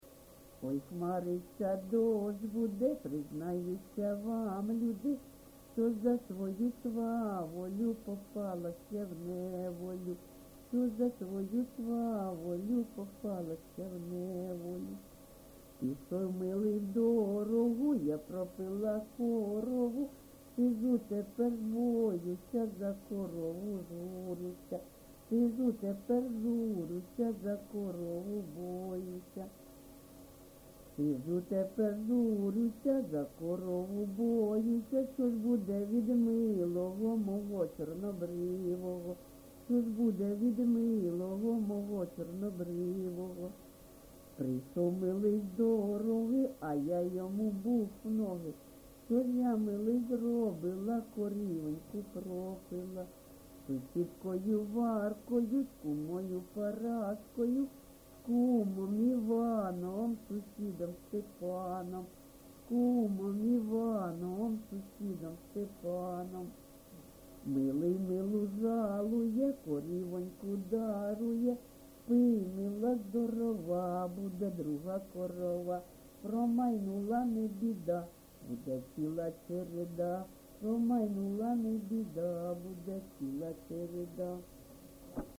ЖанрЖартівливі, Пʼяницькі
Місце записум. Бахмут, Бахмутський район, Донецька обл., Україна, Слобожанщина